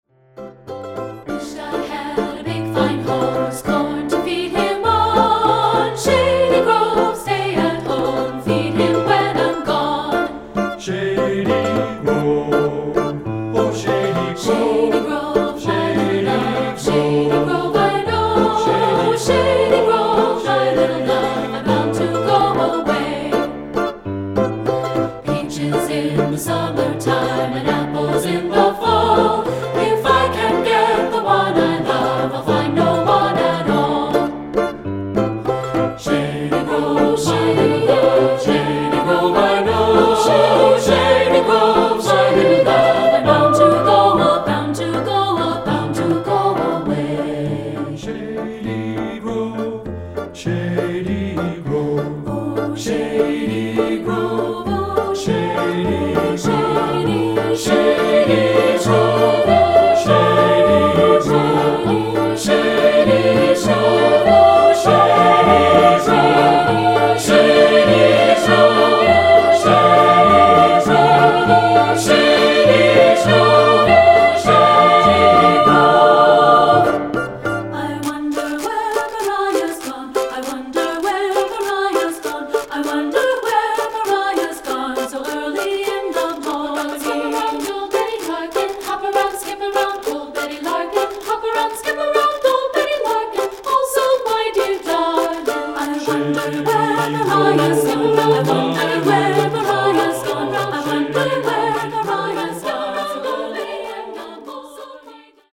Choral Folk
Appalachian Folk Song
SAB Divisi